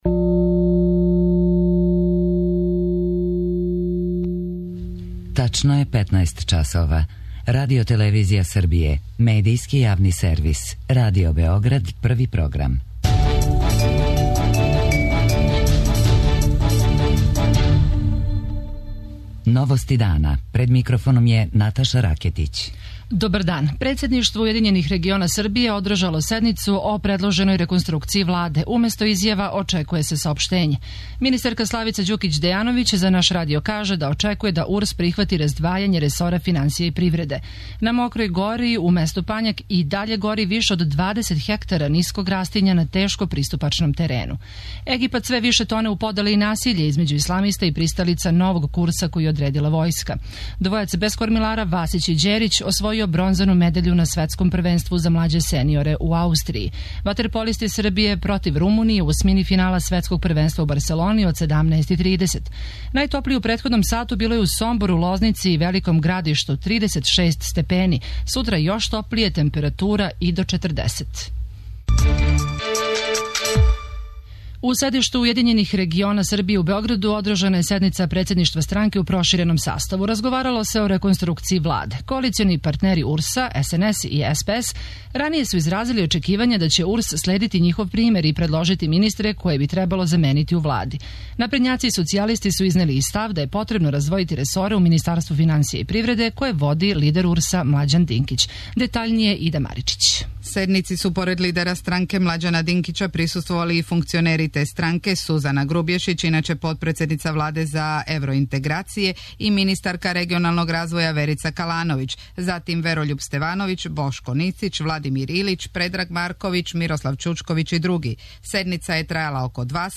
Чућемо наше репортере у Београду и Нишу.